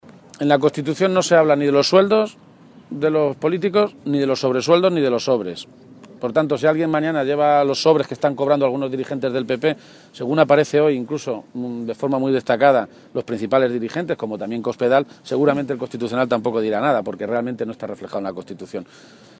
Emiliano García-Page, secretario General del PSOE de Castilla-La Mancha
García-Page se pronunciaba de esta manera esta mañana en Toledo, a preguntas de los medios de comunicación, e insistía en lo “curioso” de que se diera a conocer ayer una sentencia que aún las partes no tienen en su poder, “seguramente para tapar el desastroso dato de paro que dio ayer Castilla-La Mancha o los buenos resultados económicos” que él mismo ofreció ayer como alcalde de Toledo, con el mayor superávit municipal en la historia de la democracia.
Cortes de audio de la rueda de prensa